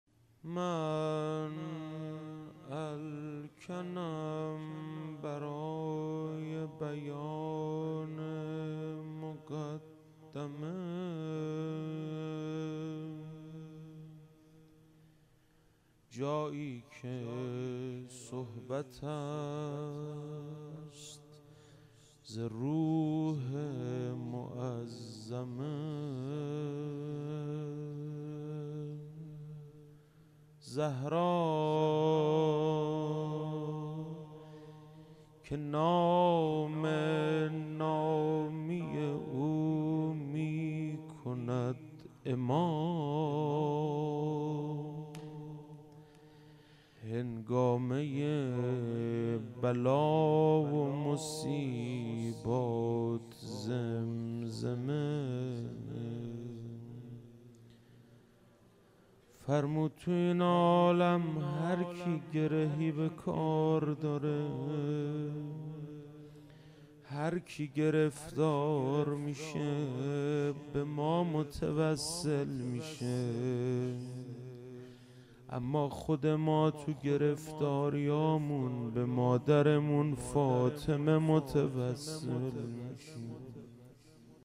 فاطمیه 96 - شب چهارم - روضه